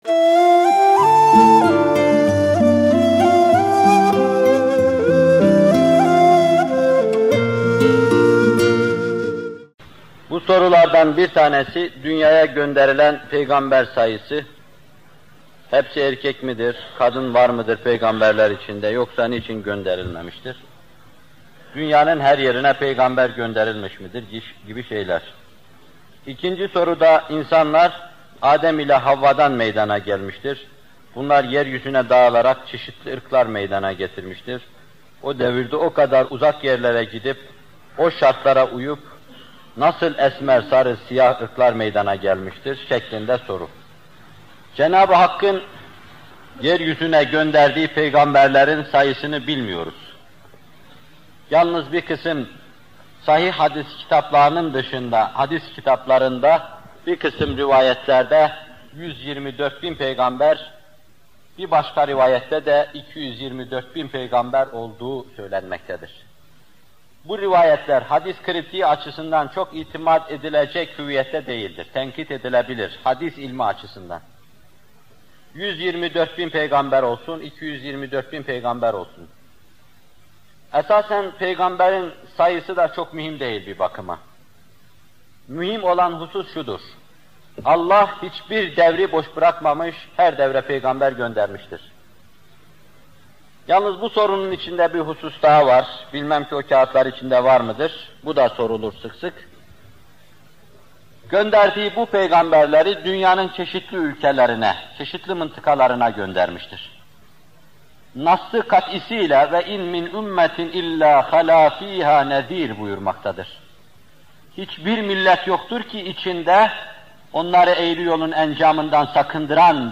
Muhterem Fethullah Gülen Hocaefendi bu videoda *Fâtır Suresi 24. ayet-i kerimesinin* tefsirini yapıyor: